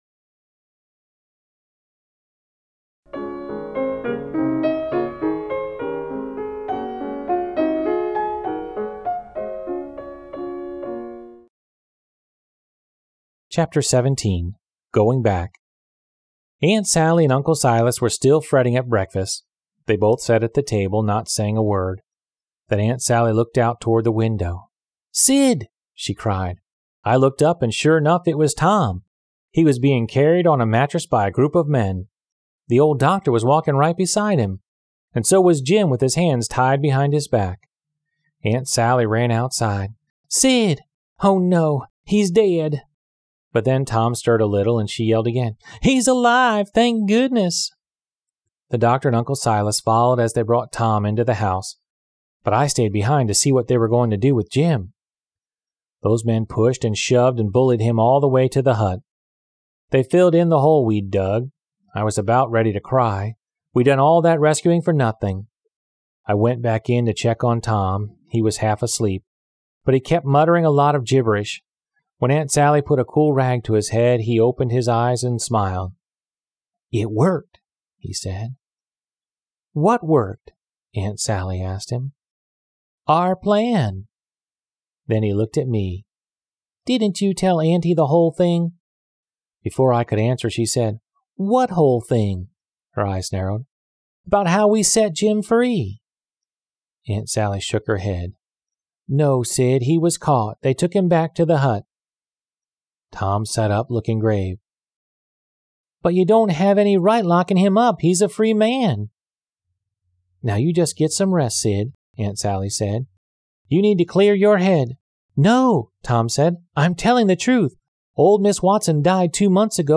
丛书臻选优质中文译本，配以导读、作家作品简介和插图，并聘请资深高考听力卷主播朗读英语有声书。